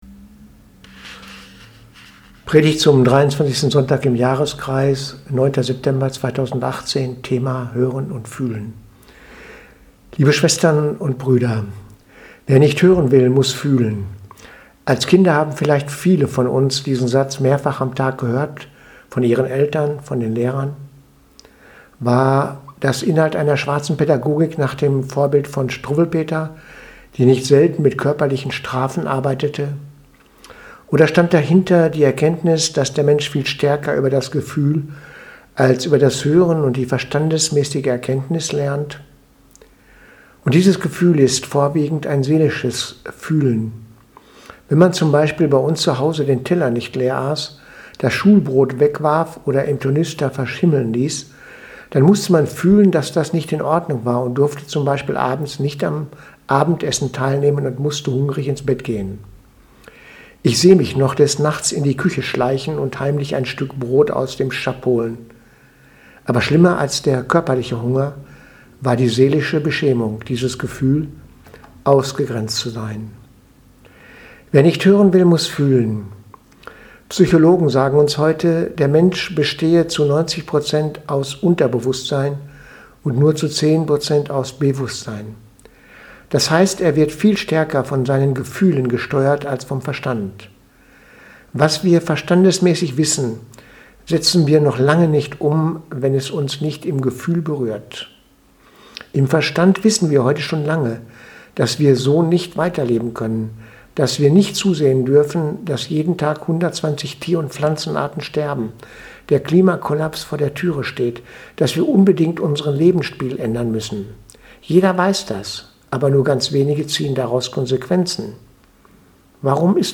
Predigt vom 9.9.2018 – Hören und fühlen